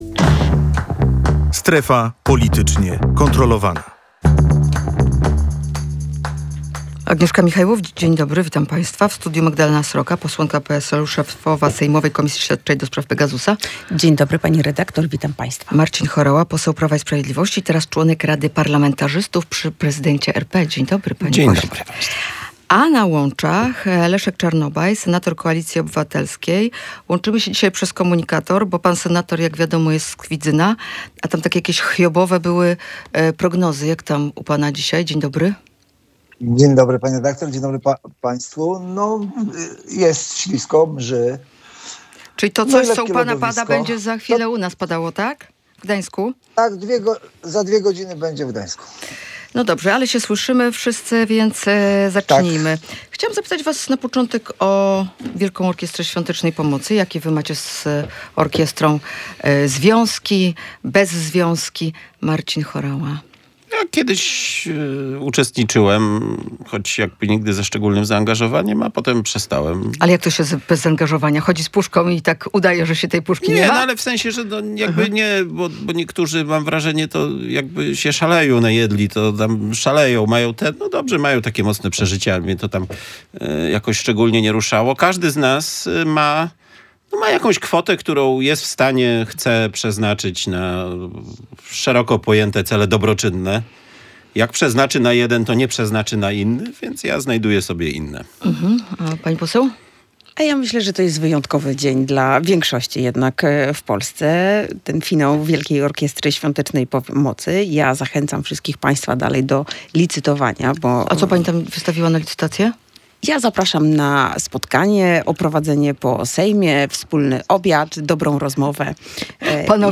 Wymiana zdań pomorskich polityków nt. WOŚP. Kto „najadł się szaleju”?